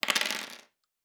Dice Multiple 4.wav